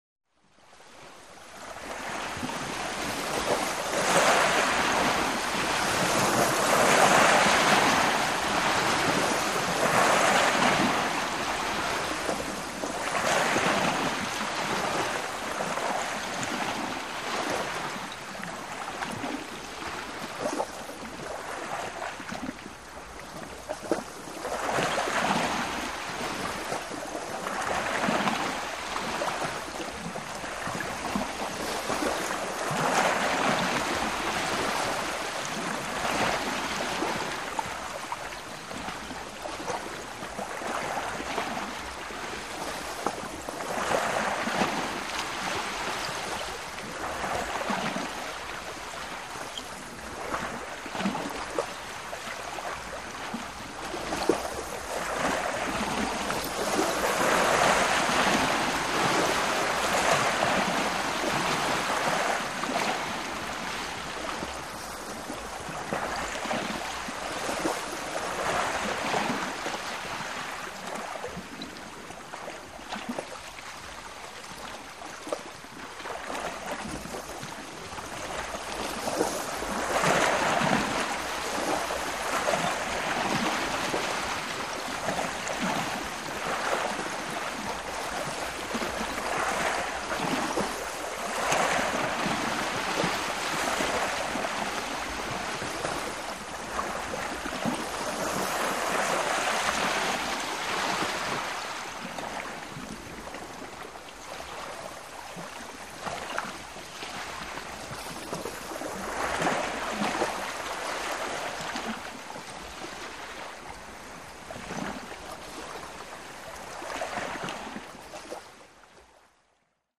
Steady Water Laps, Small Waves Wash Against Shore.